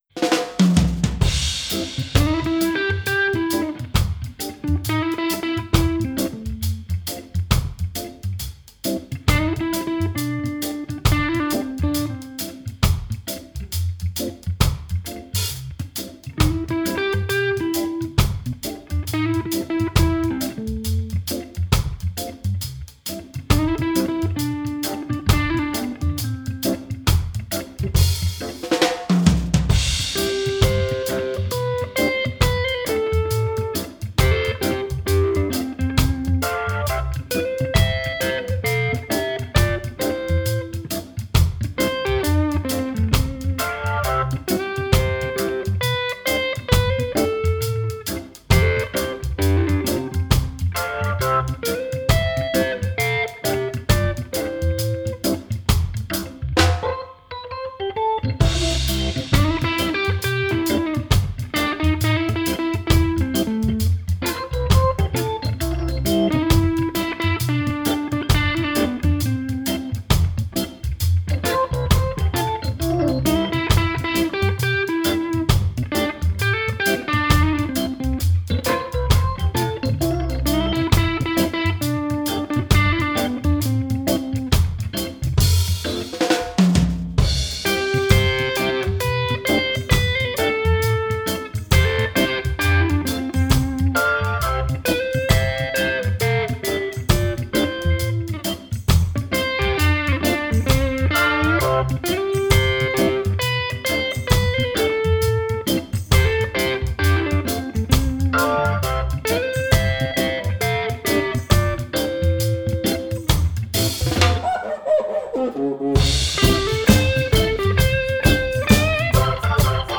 Plus que une version c'est une semplification.